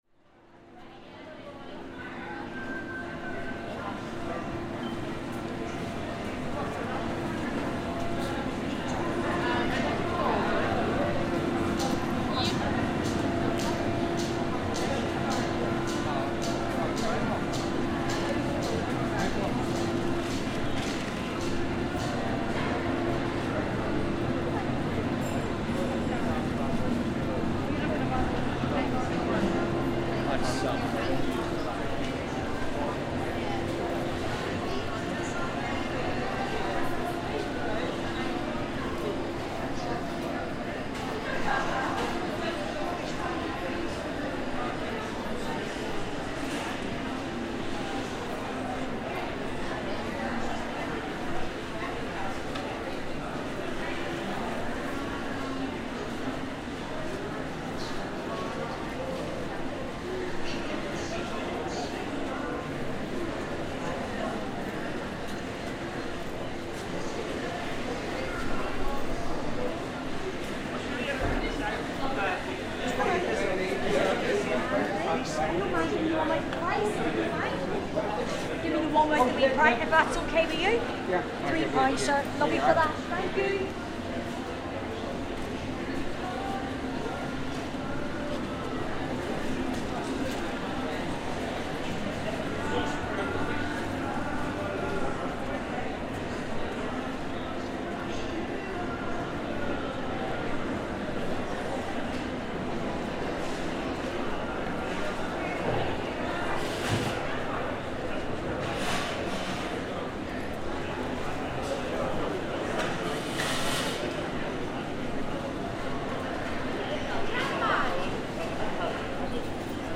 A walkthrough the famous St. George's market in Belfast, capturing the chatter of locals, goods being sold and the large, warehouse-style building's air conditioning.